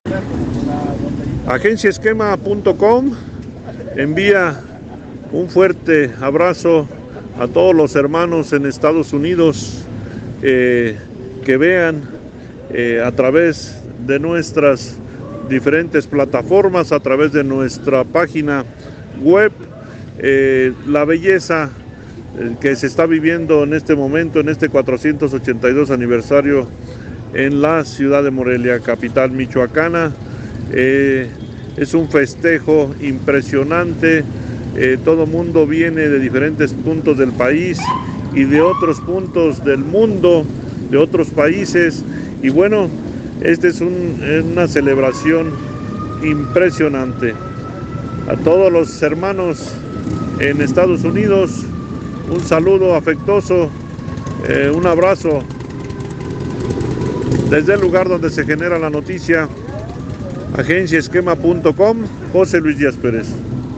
Listos los caballos de acero para iniciar la XX Caravana Motociclista en Morelia
xx-caravana-de-motos.mp3